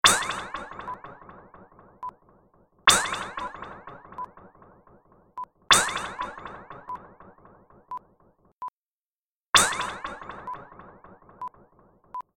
Zap Whoosh Hit (Sound FX)
Whooshing, zap, Whiz sound. Reverberant echo, Multimedia Sound Effects, Zap sounds
Zapper_plip.mp3